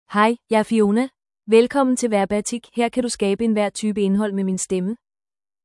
Fiona — Female Danish AI voice
Fiona is a female AI voice for Danish (Denmark).
Voice sample
Listen to Fiona's female Danish voice.
Female